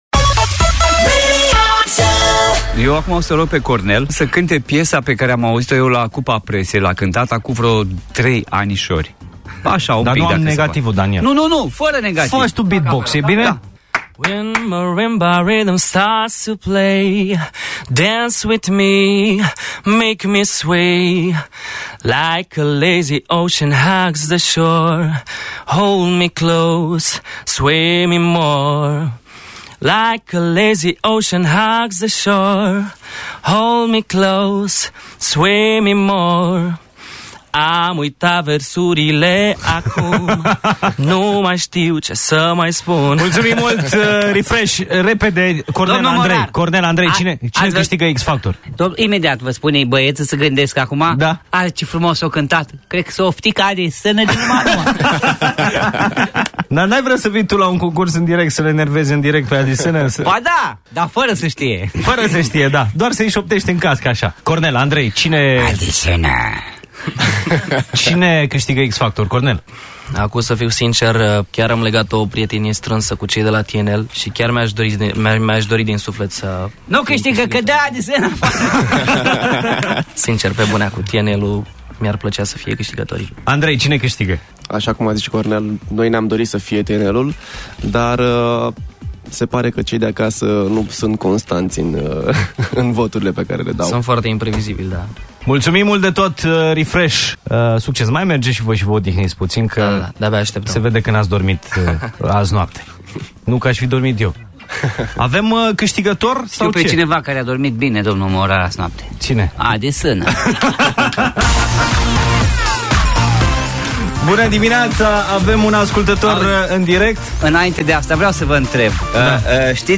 Baietii au iesit din concurs, dar asta nu i-a oprit sa ne cante live in studioul ZU.